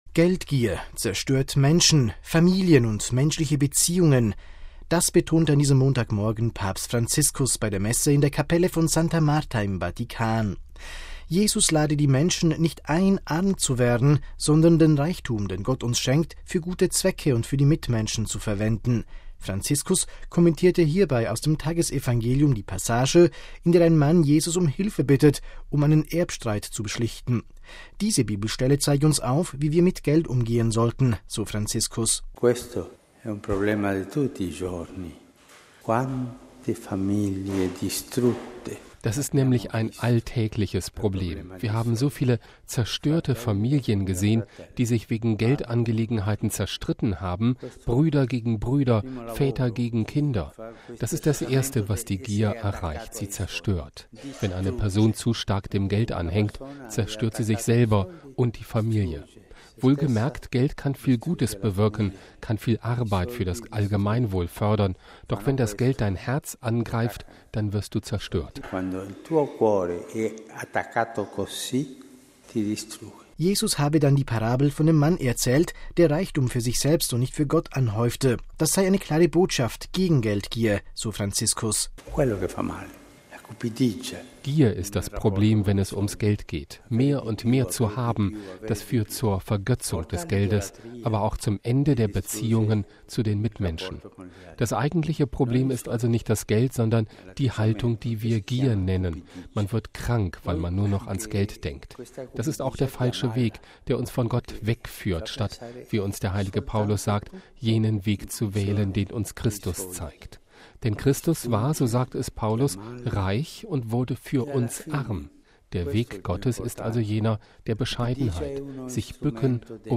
Papstpredigt: „Geldgier zerstört Familien und Beziehungen“
MP3 Geldgier zerstört Menschen, Familien und menschliche Beziehungen. Das betonte an diesem Montagmorgen Papst Franziskus bei der Messe in der Kapelle von Santa Marta im Vatikan.